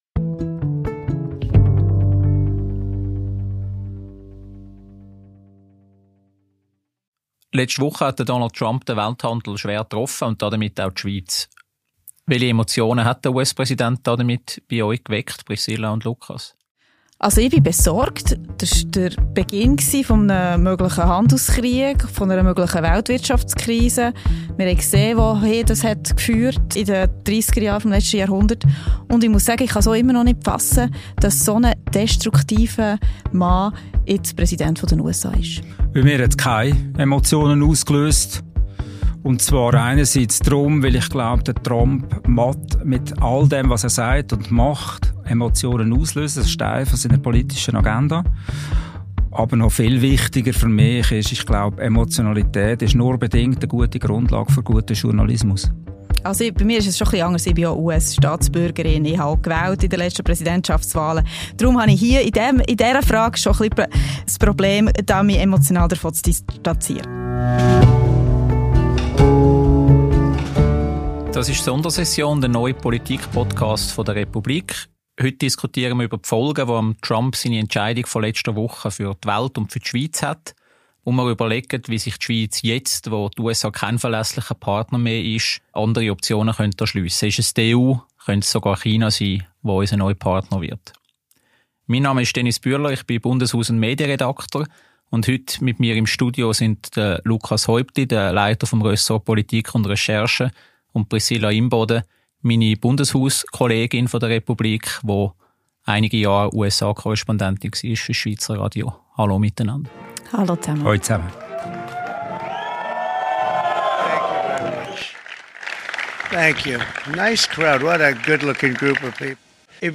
Republik «Vorgelesen» – Podcast